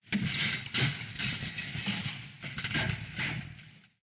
钢丝衣架 " HANGERS1 Delaymod
描述：钢丝衣架的叮当声加入调制延迟
标签： 叮当声 机械手 弹簧
声道立体声